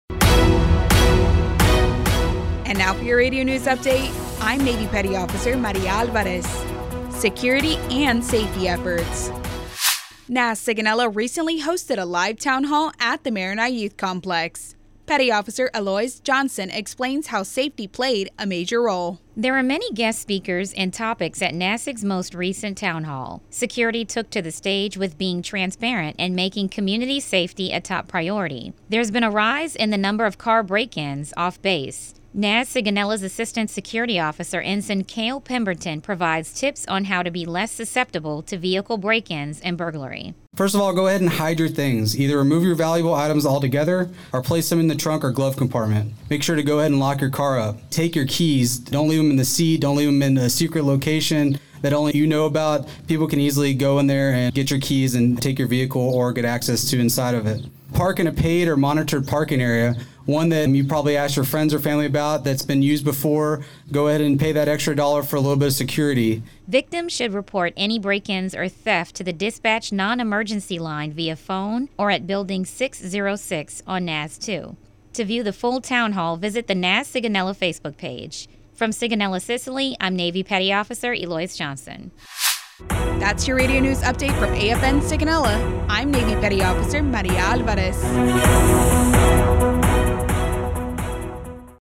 NAS SigonellaRadio News